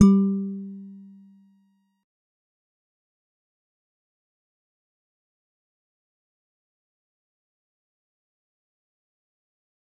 G_Musicbox-G3-mf.wav